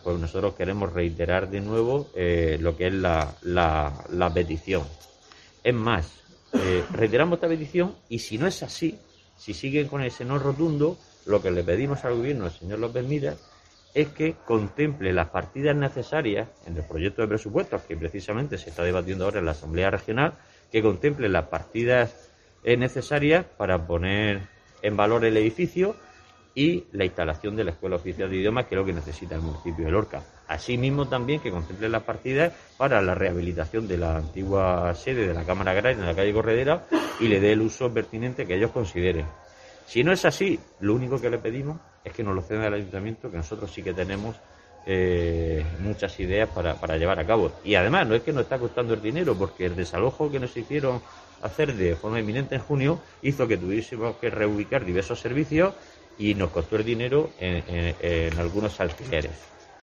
Isidro Abellán, edil de Patrimonio del Ayto Lorca